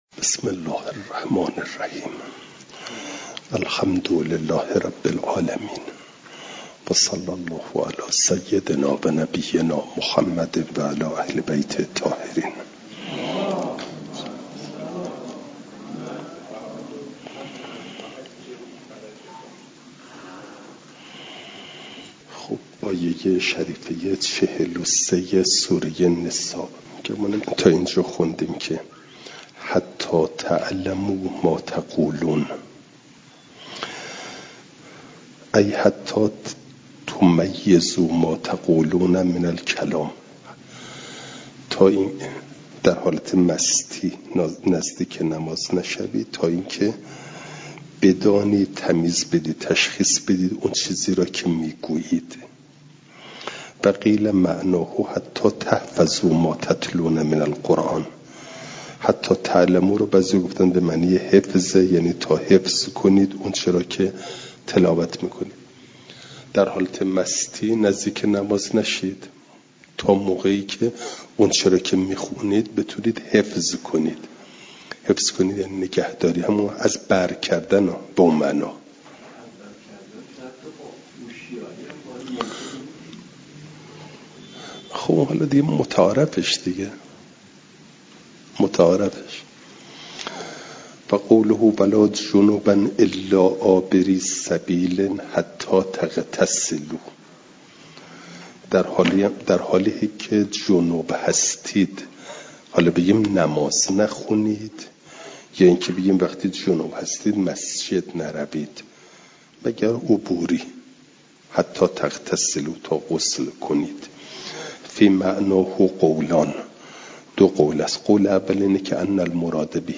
جلسه سیصد و شصت و چهارم درس تفسیر مجمع البیان